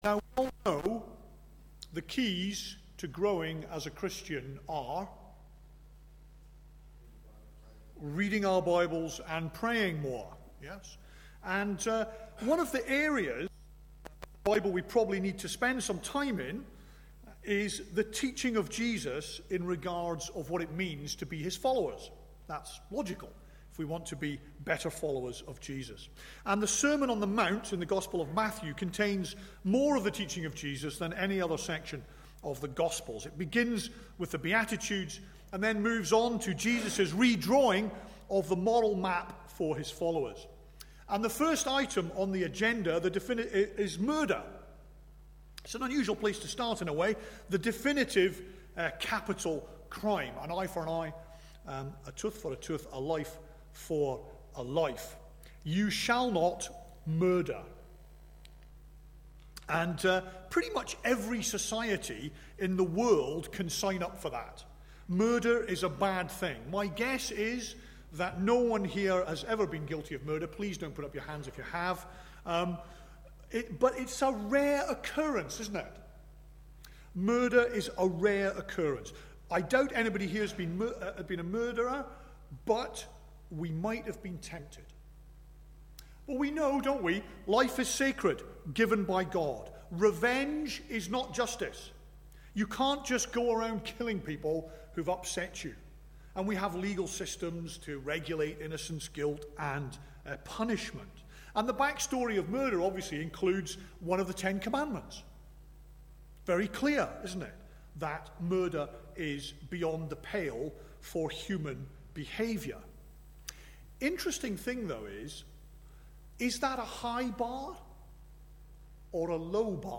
Passage: Matthew 5:21-24 Service Type: Sunday Morning